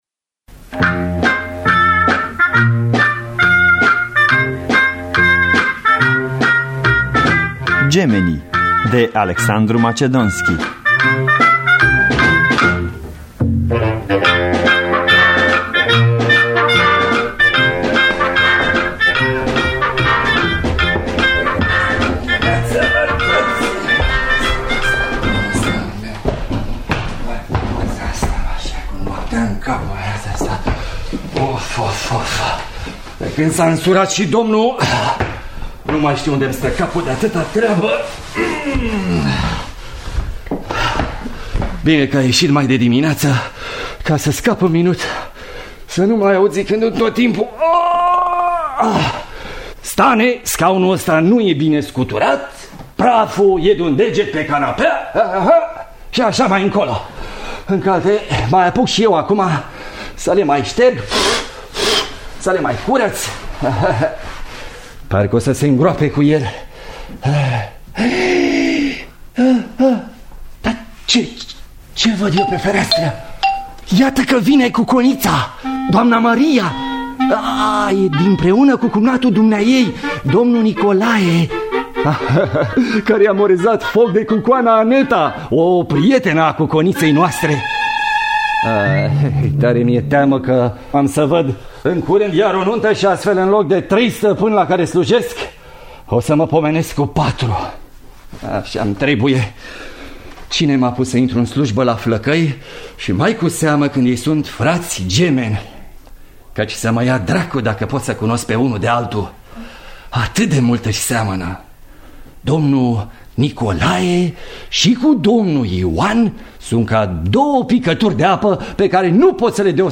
Gemenii de Alexandru Macedonski – Teatru Radiofonic Online